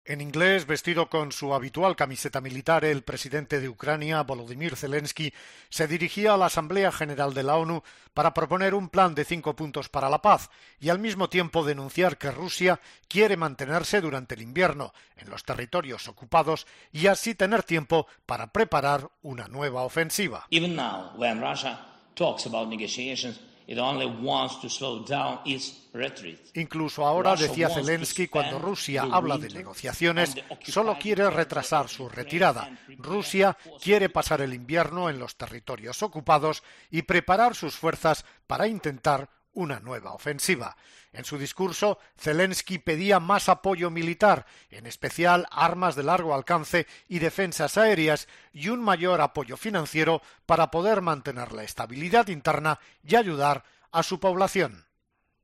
Las palabras de Zelenski ante la ONU tras el anuncio de Putin
"No podemos aceptar retrasar la guerra", dijo Zelenski en un mensaje en video a la Asamblea General de la ONU, en el que insistió en que cuando Moscú habla de negociaciones, lo único que busca es ganar tiempo.